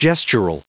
Prononciation du mot gestural en anglais (fichier audio)
gestural.wav